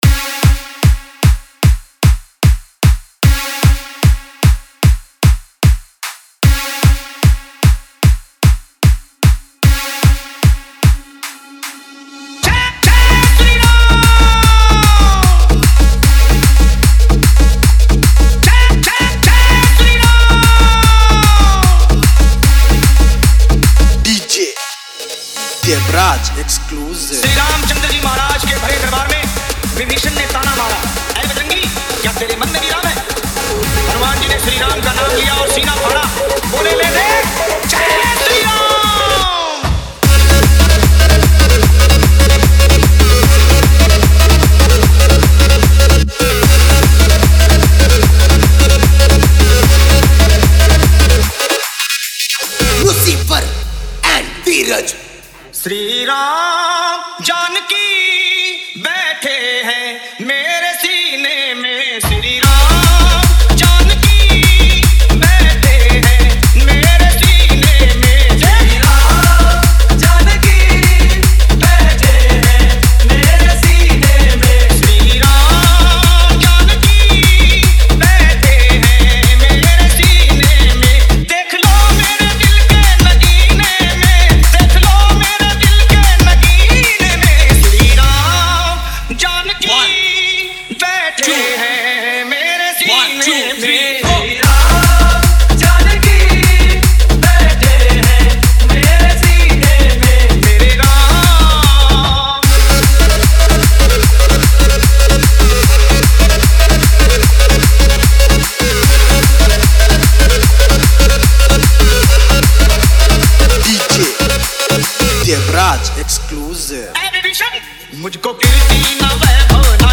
• Category :Ram Navami Special Dj